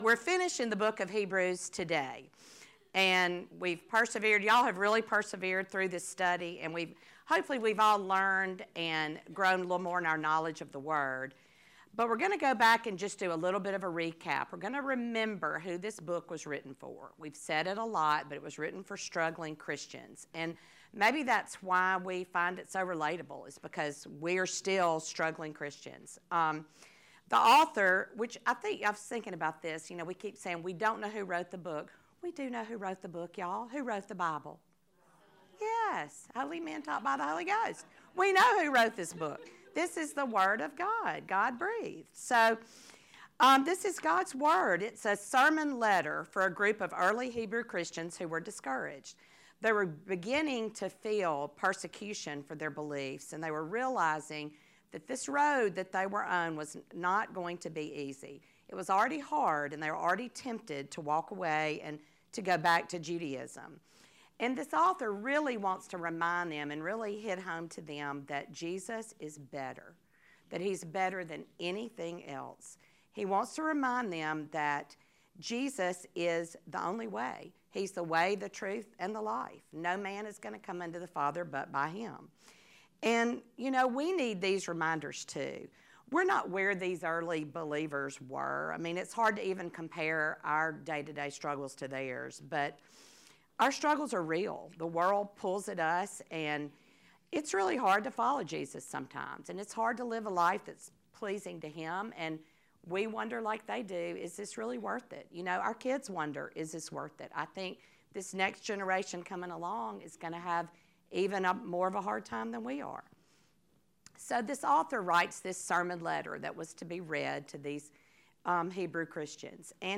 Hebrews Lesson 19